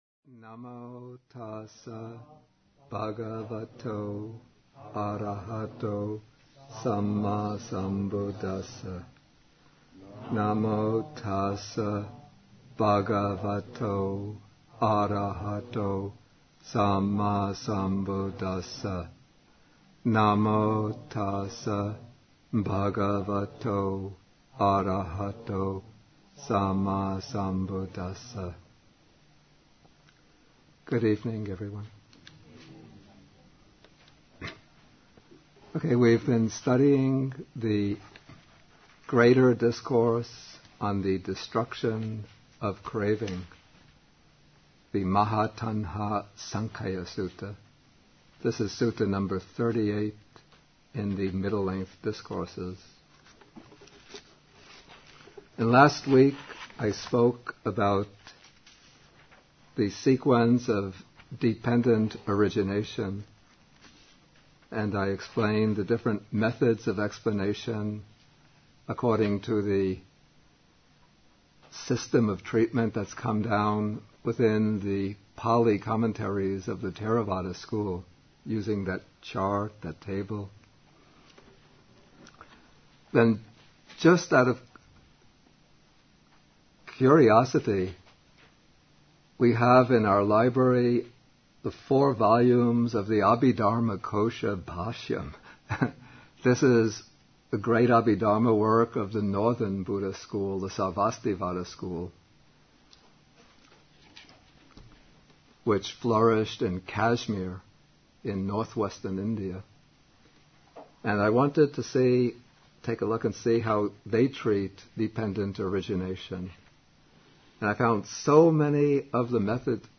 MN38, Bhikkhu Bodhi at Bodhi Monastery (lectures 90 to 94)